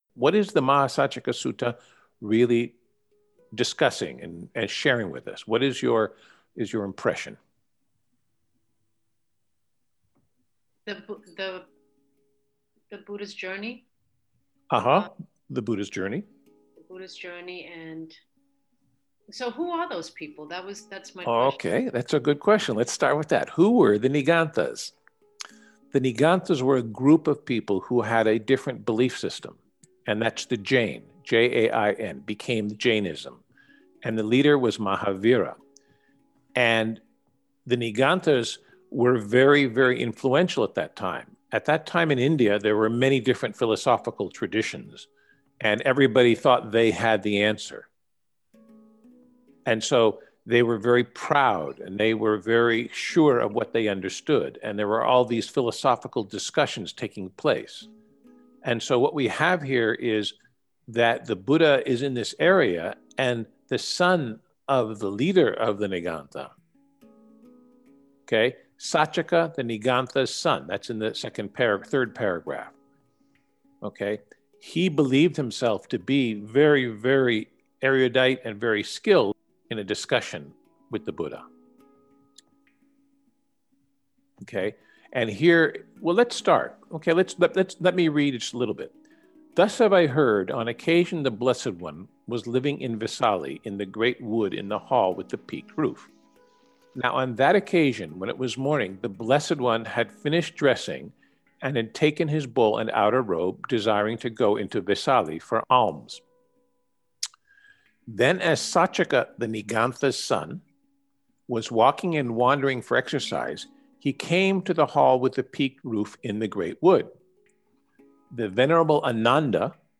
Path to Wisdom :: Conversation
path_to_wisdom_discourse_continuing_mahasaccaka_sutta.mp3